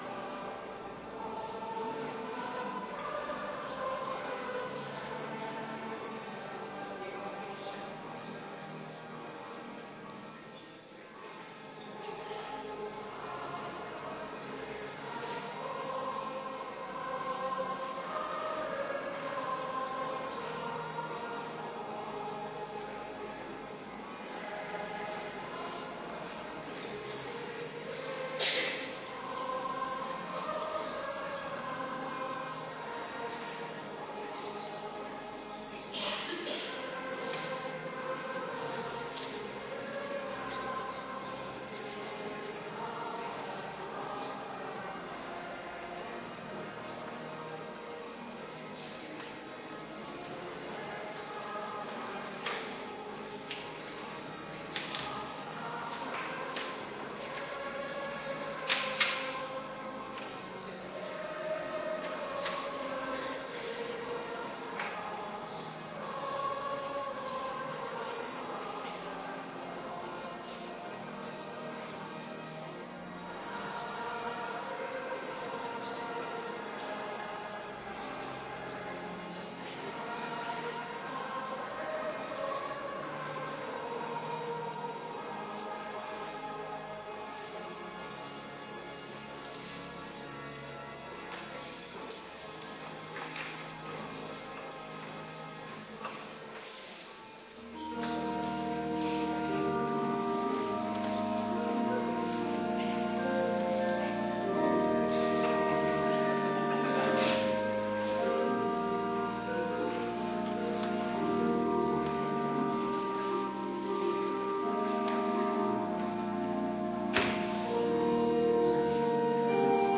Adventkerk Zondag week 50